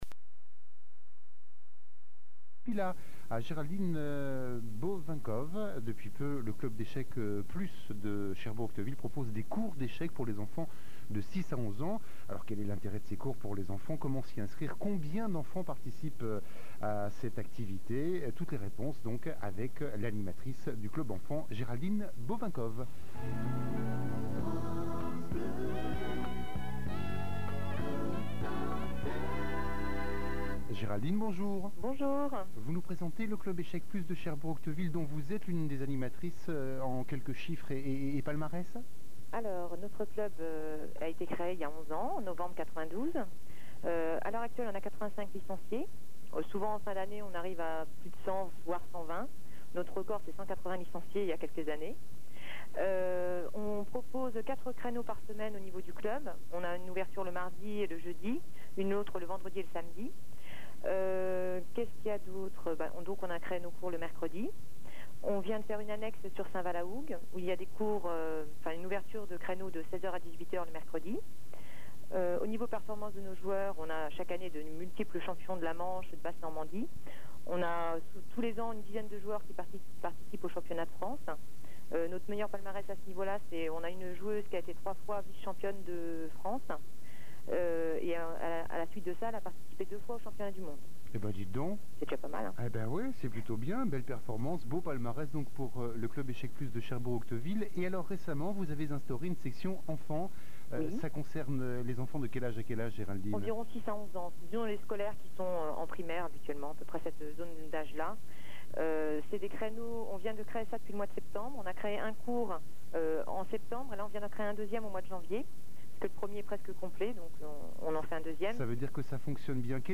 Echecs Plus à la radio
Interview complète (5234 Ko)